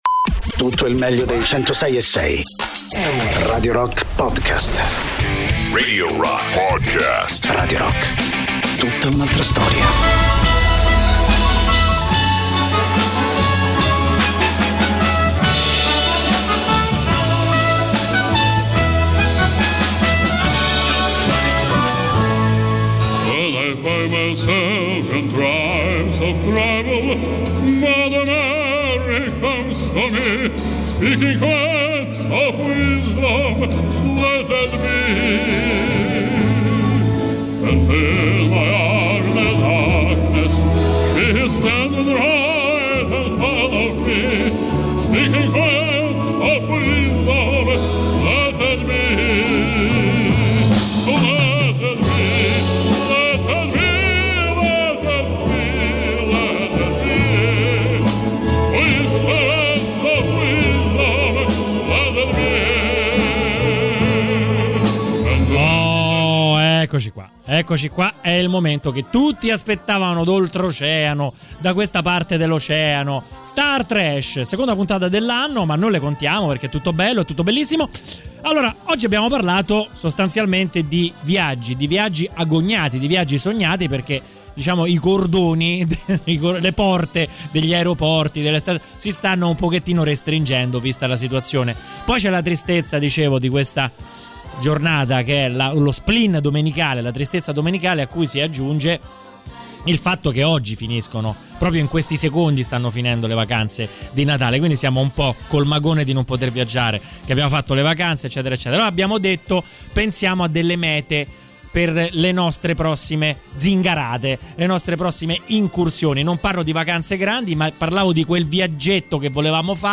In onda ogni domenica dalle 23.00 alle 24.00 sui 106.6 di Radio Rock.